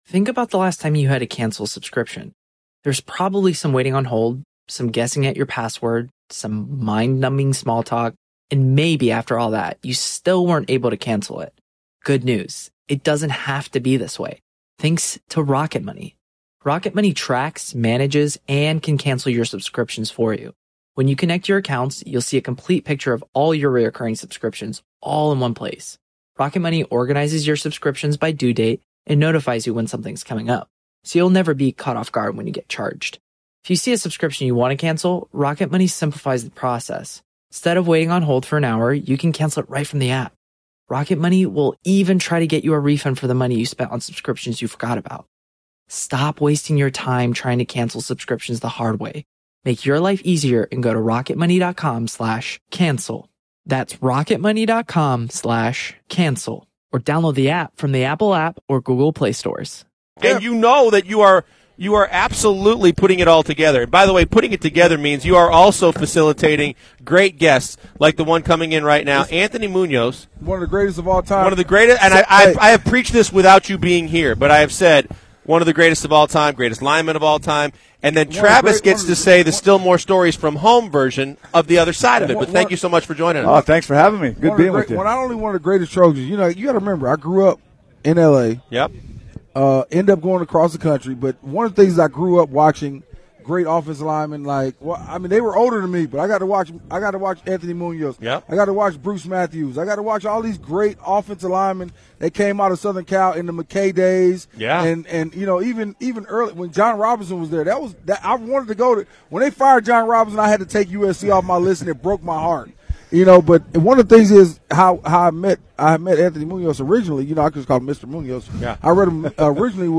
Anthony Munoz joins the show from Radio Row.